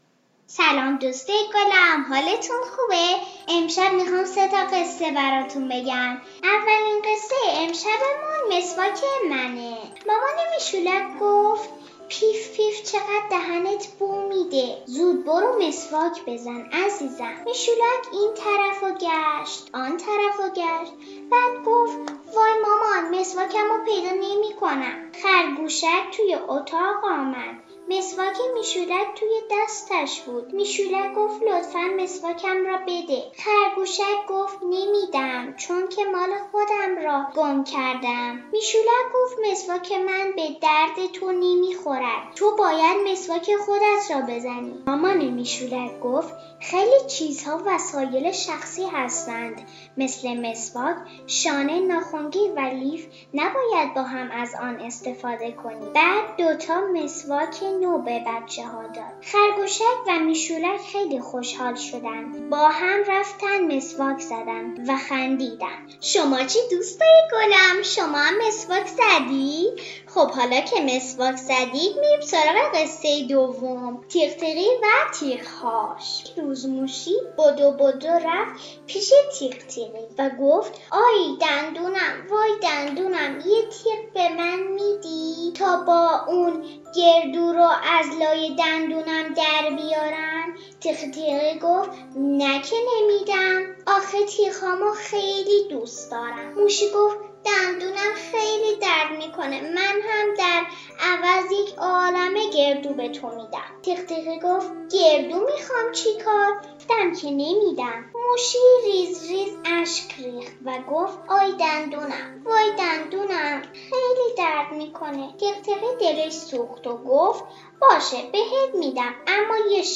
• قصه کودکان داستان کودکان قصه صوتی